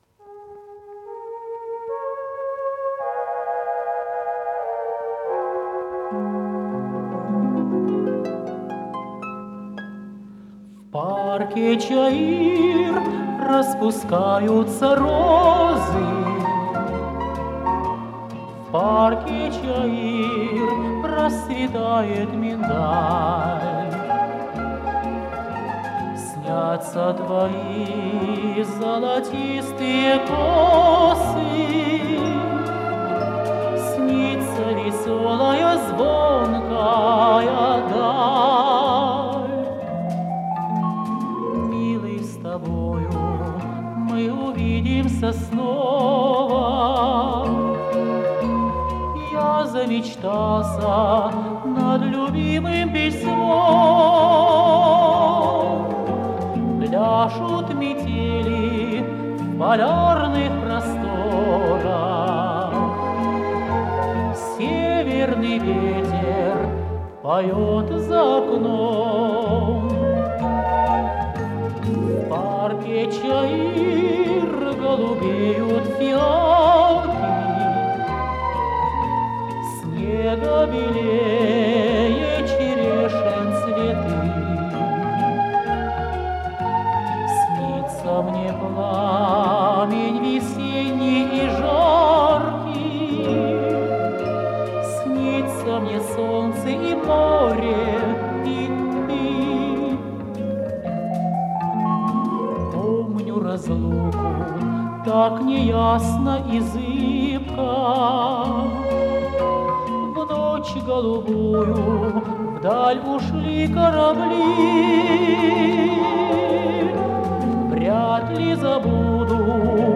три танго